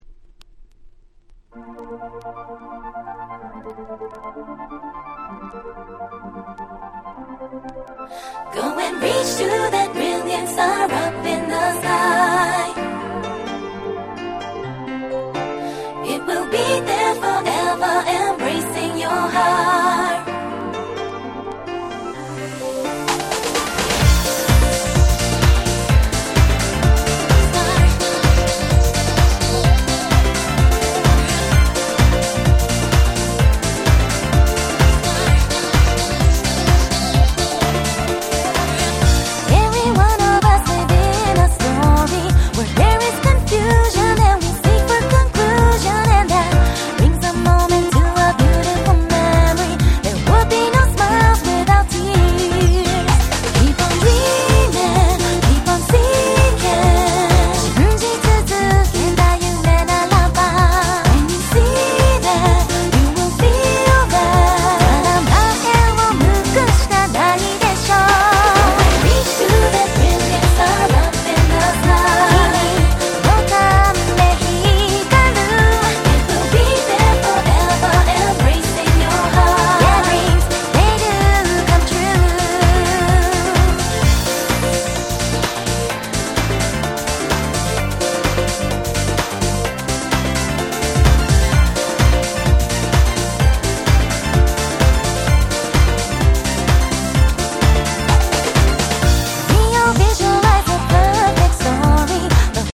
乙女ハウス